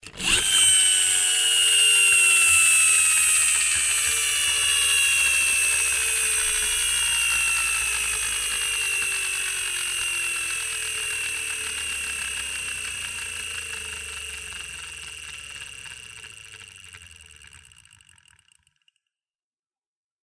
toy spring airplane motor.mp3
Recorded with a Steinberg Sterling Audio ST66 Tube, in a small apartment studio.
.WAV .MP3 .OGG 0:00 / 0:20 Type Mp3 Duration 0:20 Size 3,41 MB Samplerate 44100 Hz Bitrate 96 kbps Channels Stereo Recorded with a Steinberg Sterling Audio ST66 Tube, in a small apartment studio.
toy_spring_airplane_motor_elg.ogg